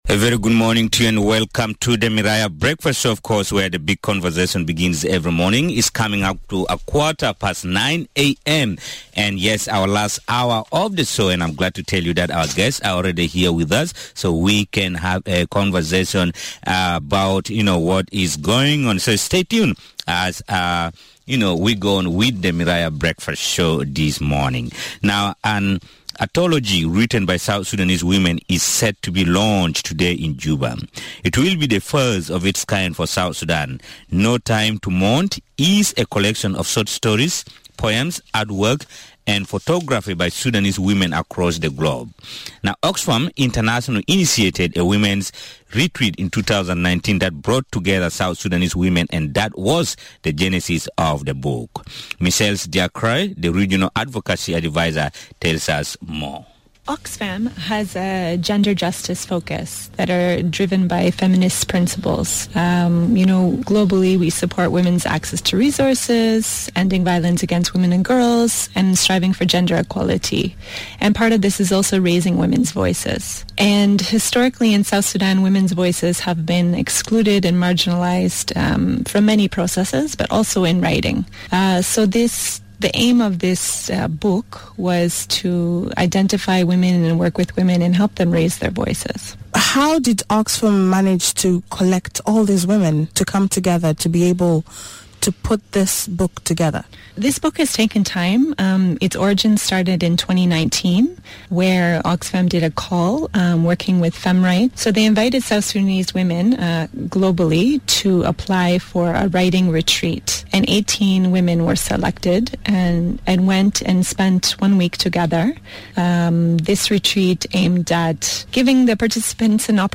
The first of its kind, ‘No Time to Mourn’ is a collection of short stories, poems, artwork, and photographs by Sudanese women across the globe. Two of the contributors to the book joined us this morning, explaining the importance of storytelling in nation building.